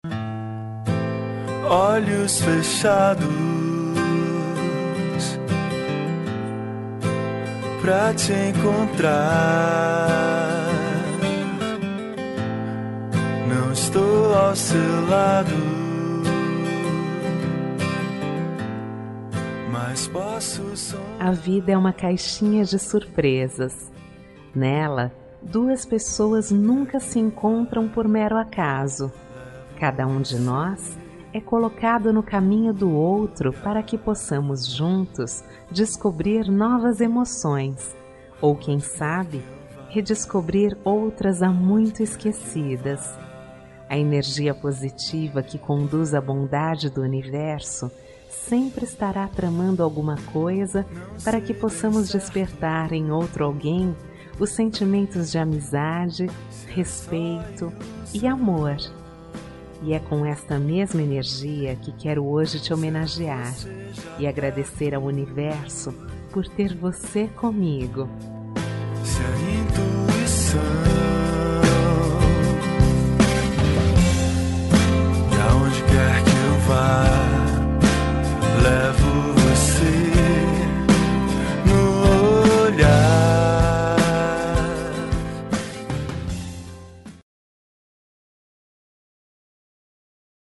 Aniversário de Namoro – Voz Feminina – Cód: 01745